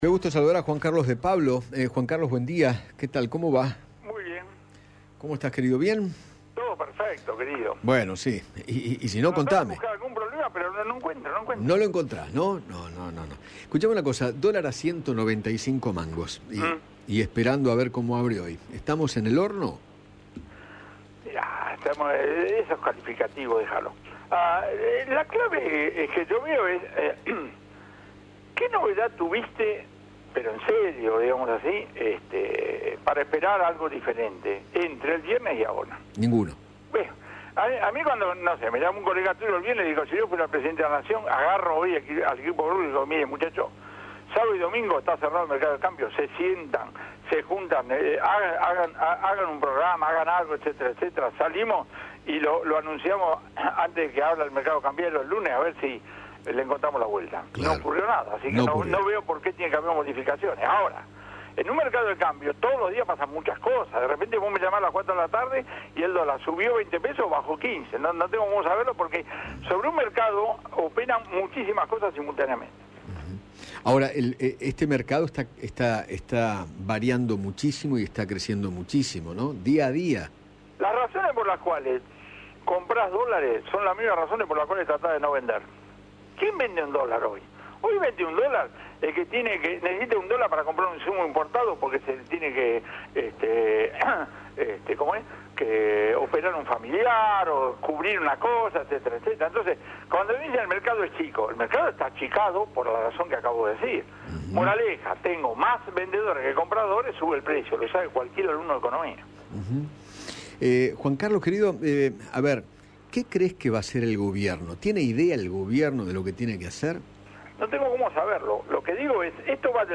El economista Juan Carlos de Pablo dialogó con Eduardo Feinmann sobre el presente económico, las medidas del ministro Martín Guzmán y Se refirió a la reacción del Gobierno frente a este complicado panorama.